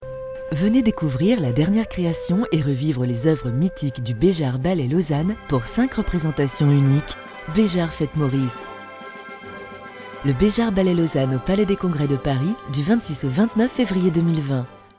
Voix Off Féminine
Type de voix : Classe, Sérieuse